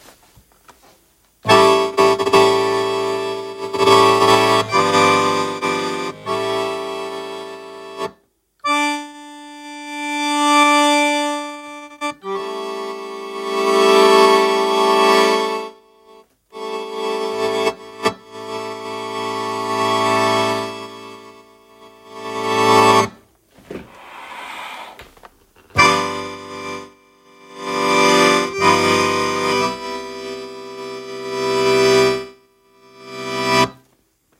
Allerdings habe ich gerade beim Vorbereiten eines Auftritts festgestellt, dass die Balgsteuerung inzwischen eine Macke zu haben scheint. Beim leisen Spiel sind deutliche Lautstärkesprünge zu hören (siehe Audio-Anhang).